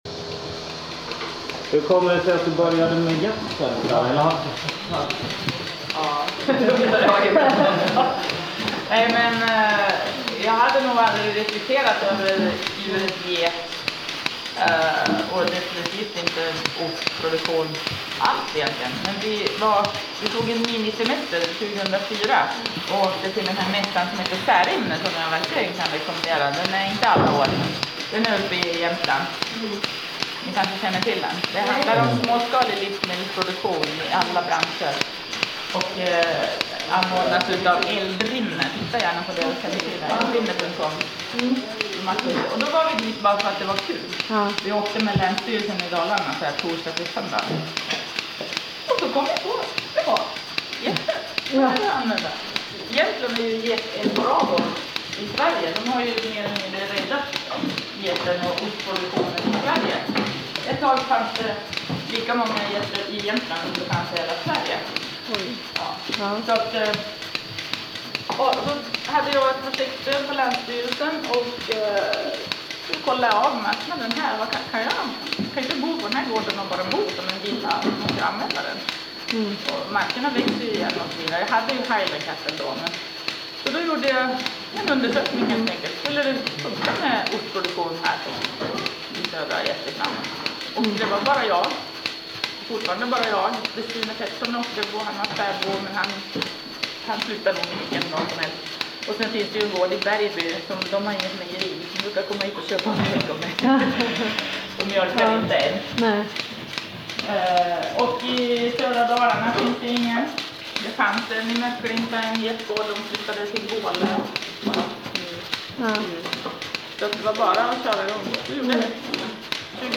Vi är med under mjölkningen